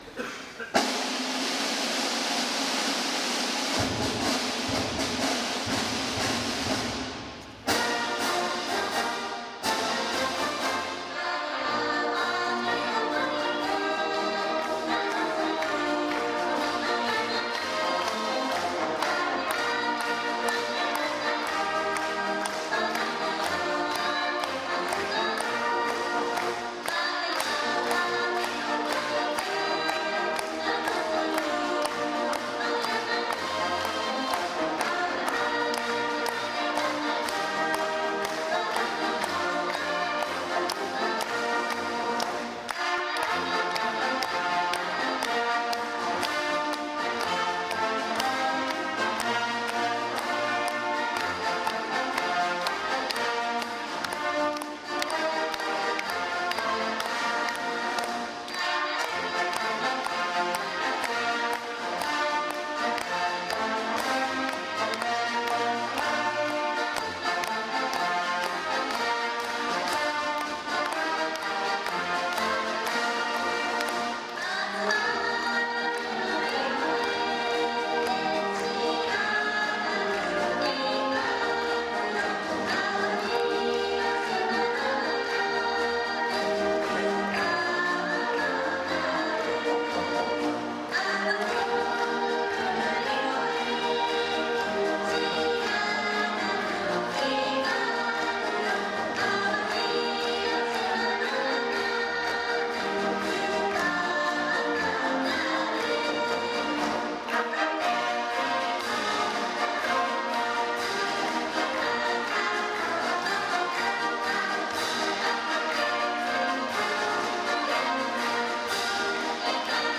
合唱付きドッソ 「合唱付き」
明石市民会館大ホール